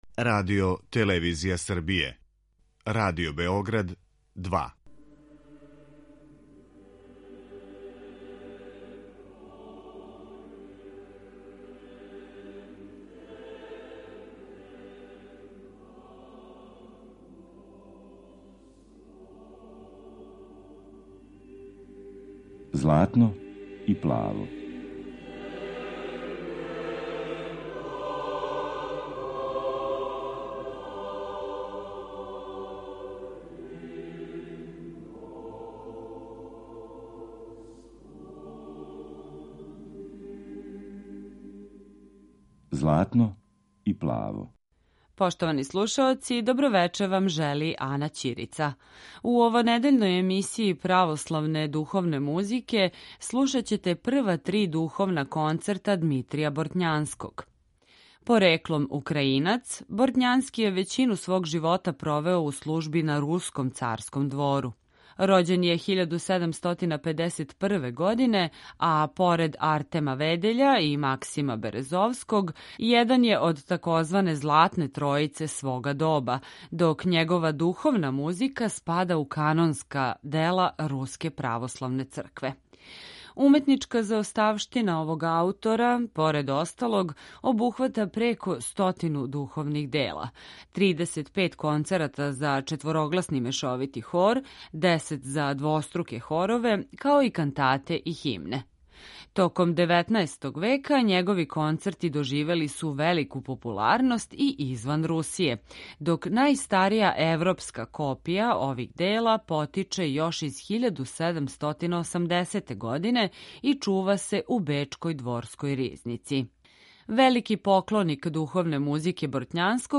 Емисија посвећена православној духовној музици.
Вечерас емитујемо прва три Духовна концерта у извођењу хора Руске државне капеле којим диригује Валериј Пољански.